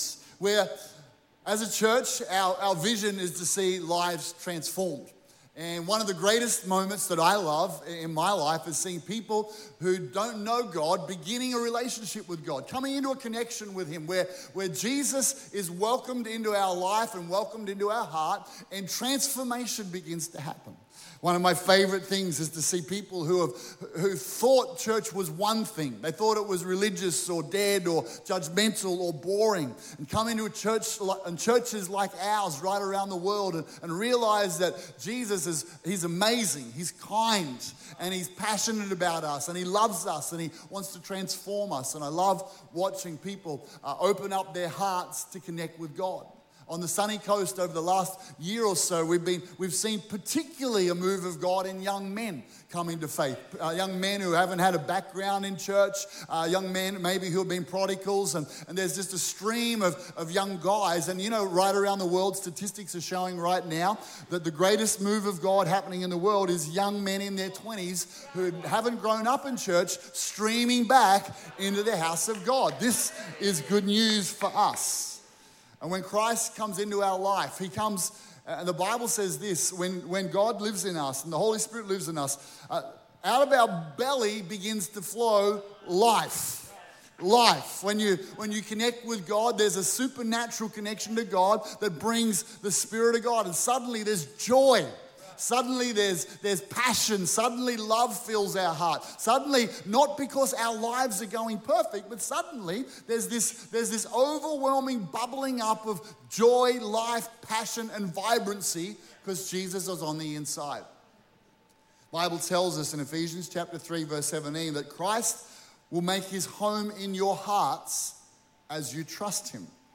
This message is from one of our Sunday church services.